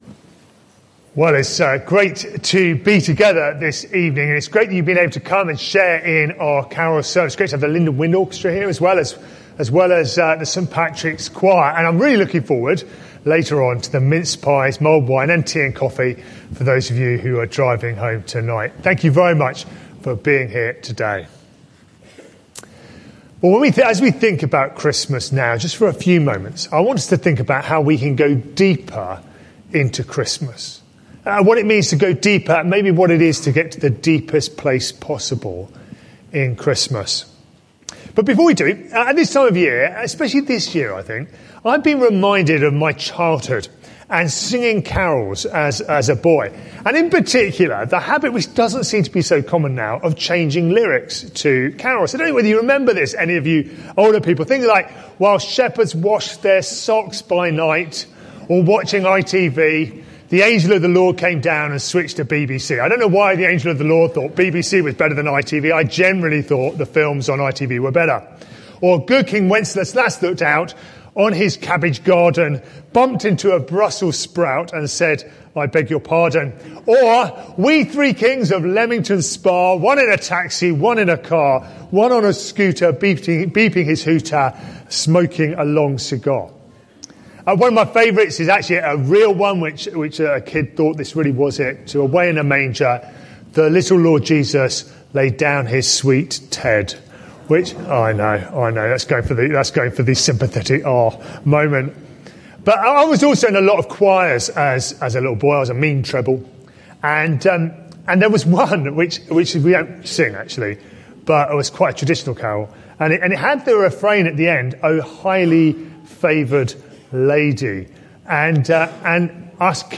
Carol Service